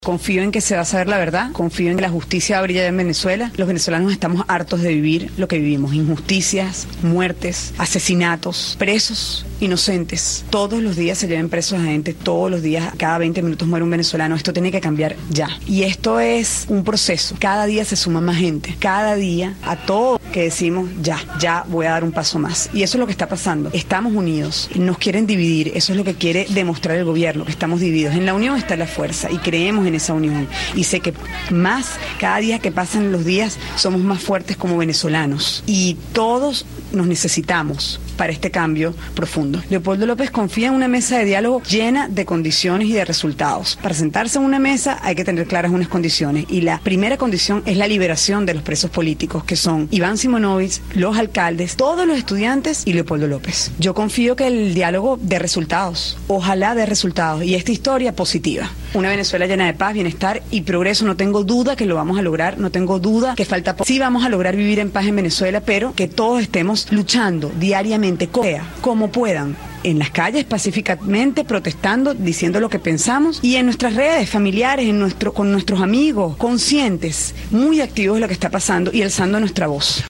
Lilian Tintori, conversa con Radio Martí